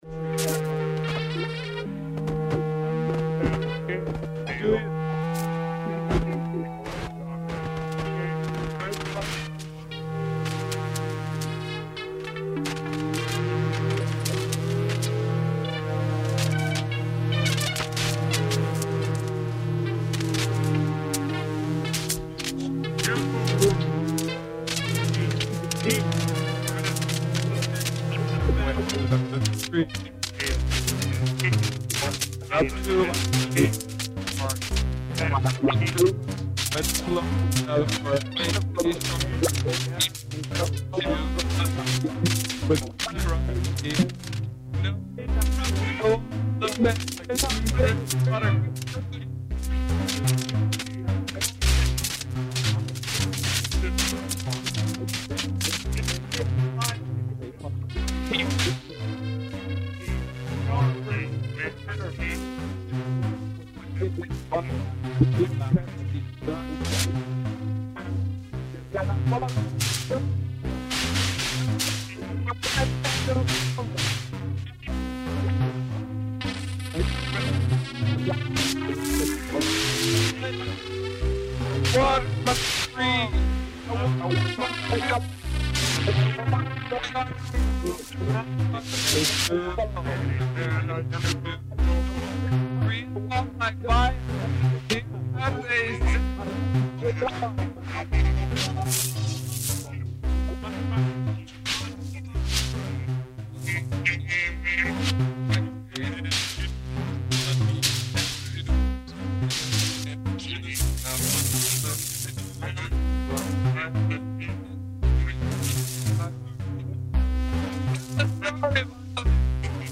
Buchlaシンセサイザーもガラクタも面白い音を出すための道具として彼らにとって同等だったようだ。
CalArts電子音楽スタジオでの録音だけではなく、日常的に作っていた奇妙な音群から厳選した内容のレコードだ。